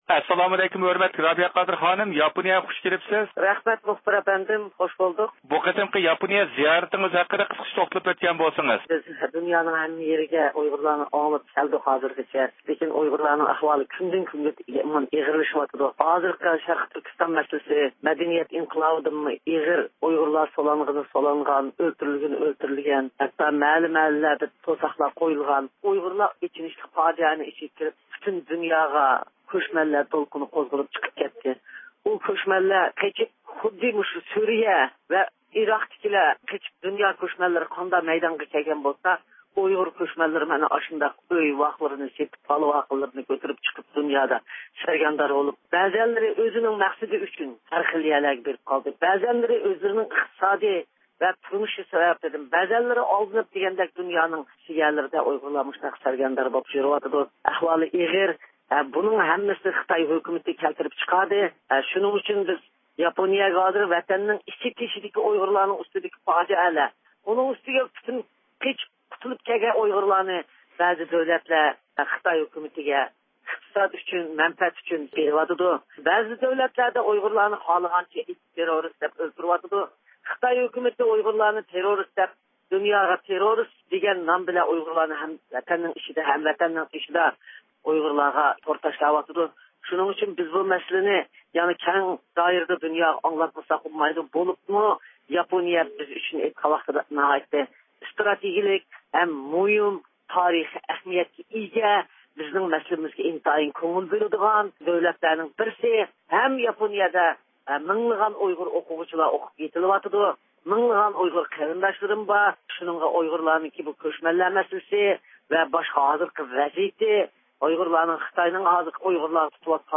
بىز بۇ ھەقتە تولۇق مەلۇماتقا ئېرىشىش ئۈچۈن نەق مەيدانغا تېلېفون قىلىپ دۇنيا ئۇيغۇر قۇرۇلتىيى رەئىسى رابىيە قادىر خانىم بىلەن سۆھبەت ئېلىپ باردۇق.